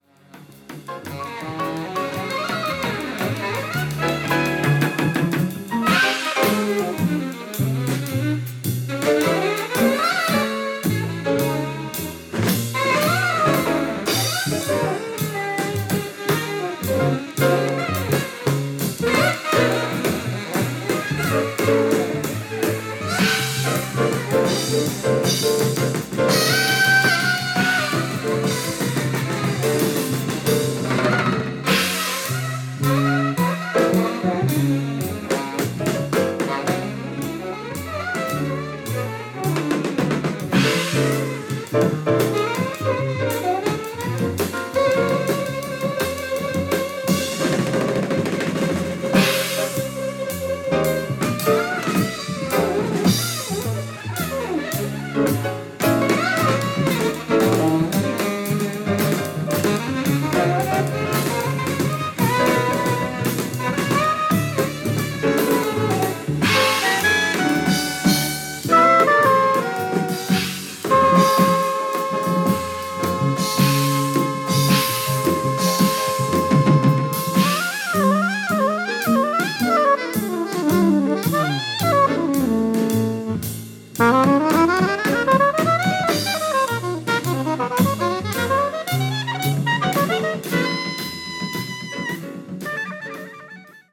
76年シカゴで録音されたヤツです。